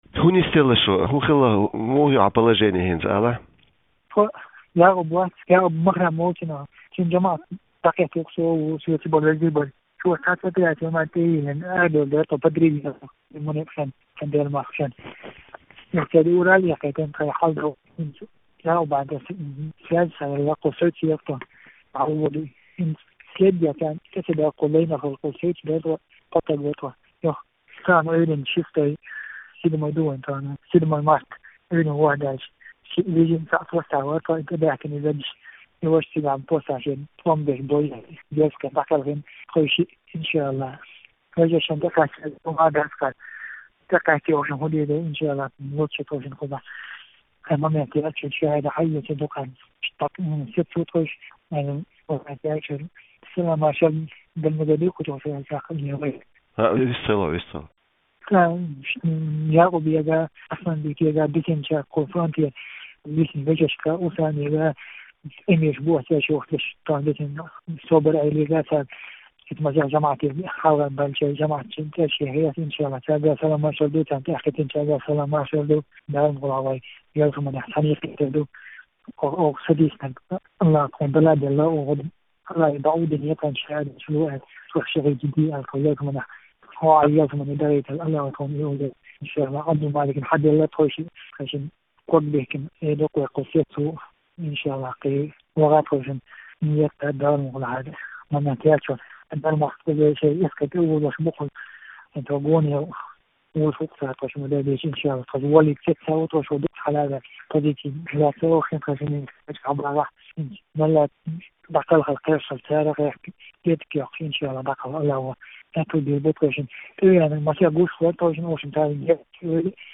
Маршо Радиоца дина къамел (юьззина верси)